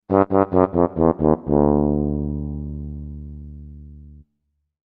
جلوه های صوتی
دانلود صدای شیپور باختن از ساعد نیوز با لینک مستقیم و کیفیت بالا
برچسب: دانلود آهنگ های افکت صوتی اشیاء